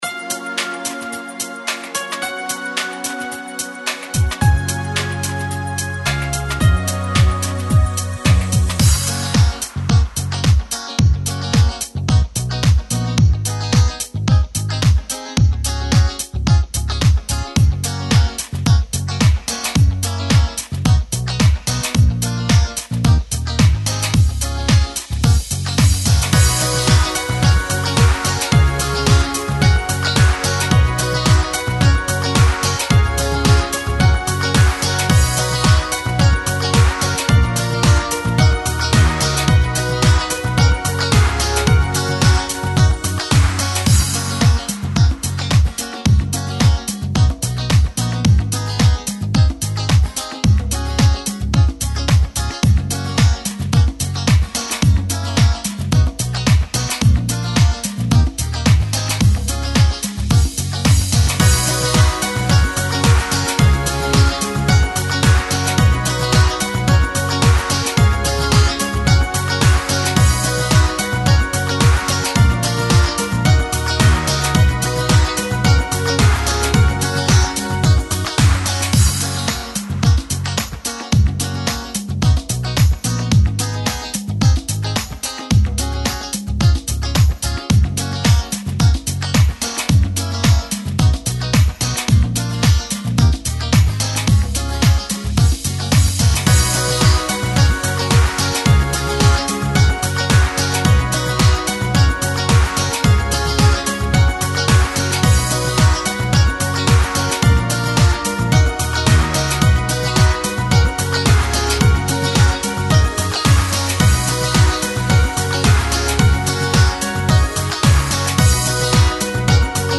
• Категория: Детские песни
Школьные песни